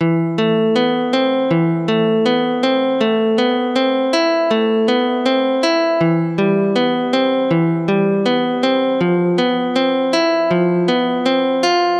吉他合成器
描述：寒冷的吉他合成器与沉重的reeverb，可以与各种不同类型的音乐搭配。
Tag: 80 bpm Chill Out Loops Synth Loops 2.02 MB wav Key : G